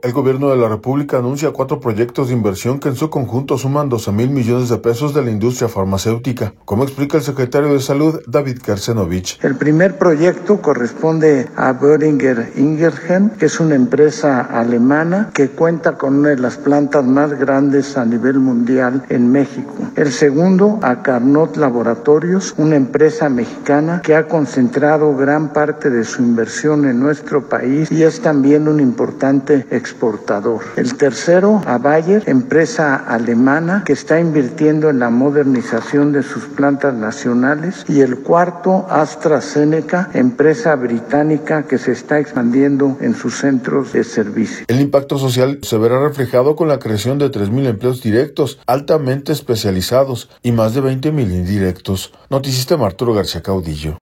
El Gobierno de la República anuncia cuatro proyectos de inversión que en su conjunto suman 12 mil millones de pesos de la industria farmacéutica, como explica el secretario de Salud, David Keeshenobich.